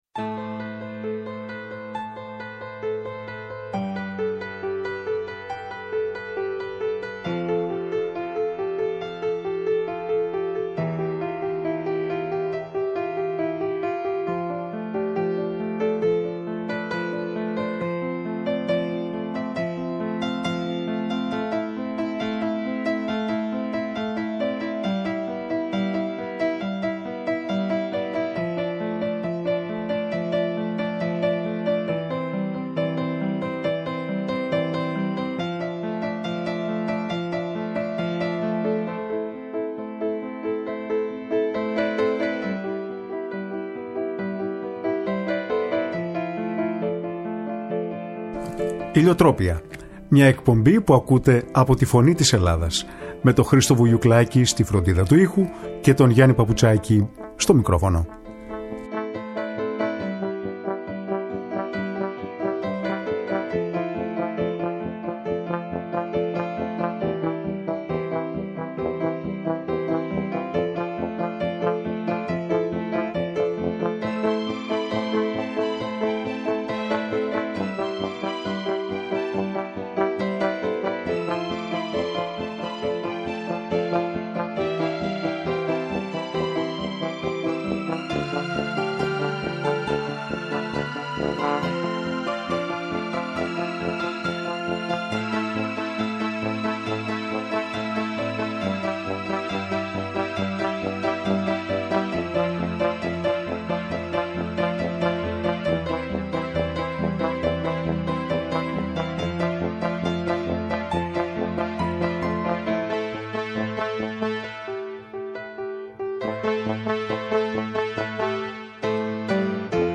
διάβασε ποιήματα δικά της και των: Ελύτη, Γκάτσου, Βαρβέρη, Δημουλά κ.ά που αναφέρονται στις Κυκλάδες